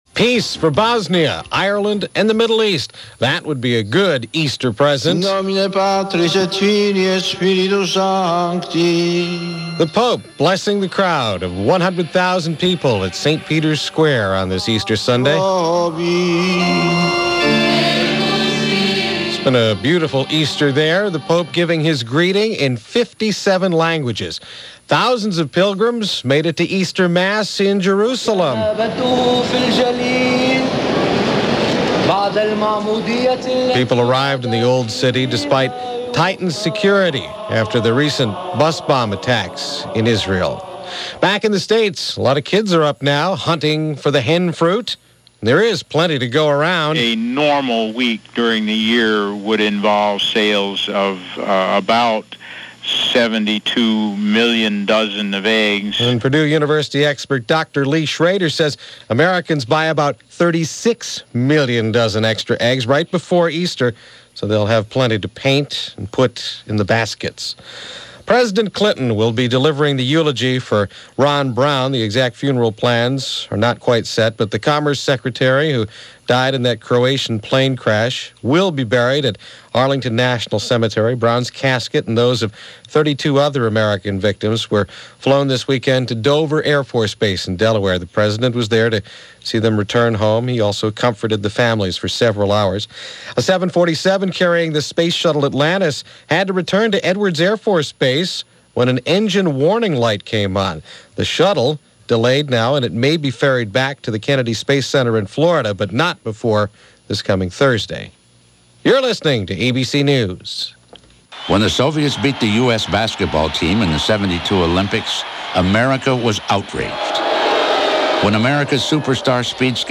April 7, 1996 - The Taking Of Ted the Hermit - Capturing The Unabomber - news for this week in 1996 as told by ABC Radio World News This Week.